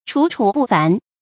楚楚不凡 注音： ㄔㄨˇ ㄔㄨˇ ㄅㄨˋ ㄈㄢˊ 讀音讀法： 意思解釋： 形容人才出眾，不同尋常。